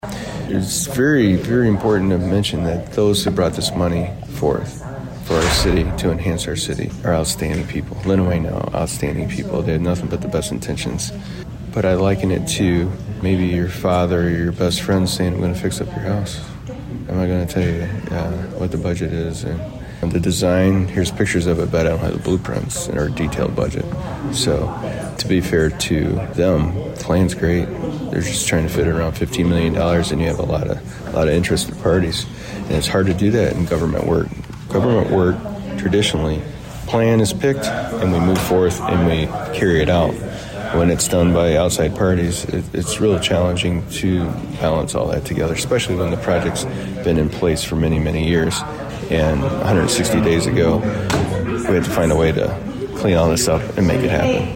WLEN News spoke with Chad Baugh after the meeting, and he expressed his concerns with Lenawee Now despite his general support for their projects…
Adrian City Administrator Chad Baugh.